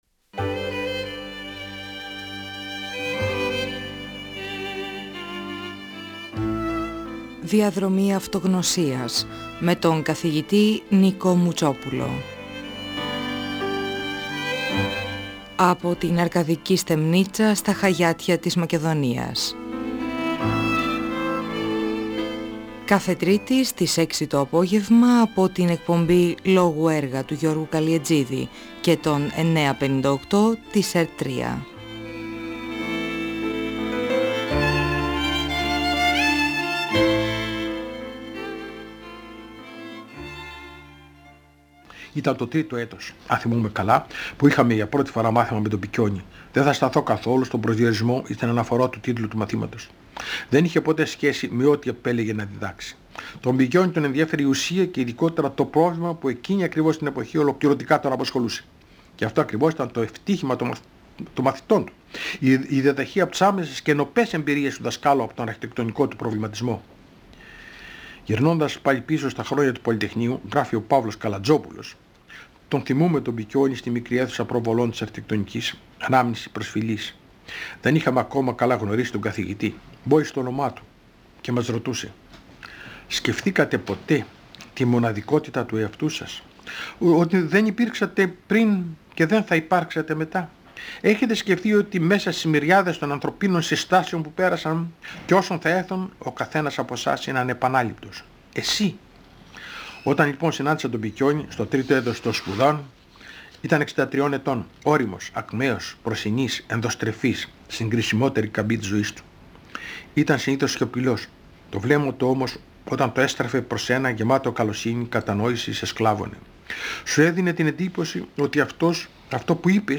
Διαβάζει αποσπάσματα από το περιοδικό «Ζυγός» για το ρόλο της αρχιτεκτονικής, της ζωγραφικής και της τέχνης γενικότερα. Αναφέρεται στις αναγκαστικές επιλογές σπουδών, στο ελληνικό τοπίο, στην αγάπη του για τη ζωγραφική και στους: Ν. Δραγούμη, Ν. Εγγονόπουλο, Γεώργιο de Κίρικο, Μαξ Κλίνγκερ, Φ. Κόντογλου, Γ. Μπουζιάνη, Γ. Τσαρούχη, Κ. Φρισλάντερ, Γ. Χατζηκυριάκο-Γκίκα.